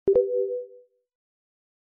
点击1.wav